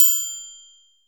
Percs (13).wav